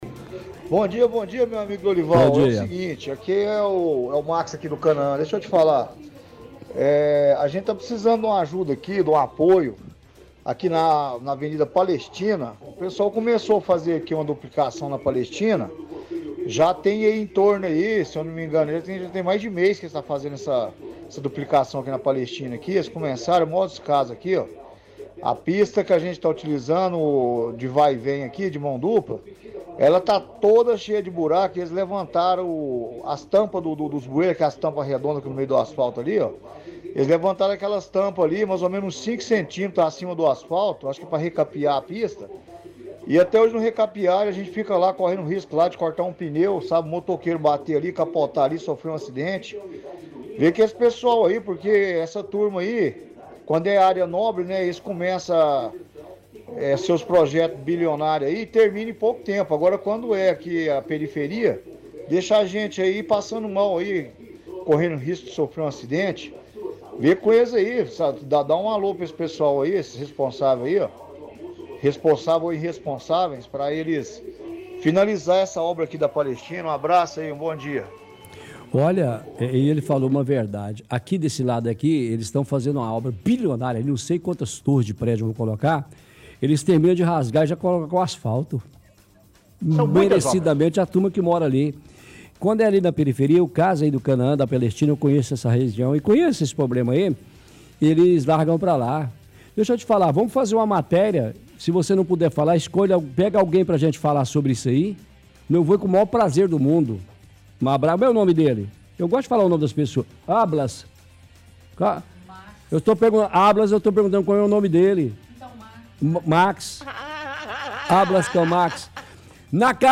– Ouvinte do bairro Canaã diz que na avenida Palestina foi iniciada uma obra há mais de um mês na pista, reclama que está cheia de buracos, levantaram as tampas para recapear a pista, mas não terminam. Diz que corre risco de acidentes, e que quando são obras em bairros mais valorizados, resolvem rápido, mas em bairros mais afastados ou mais humildes, demoram muito.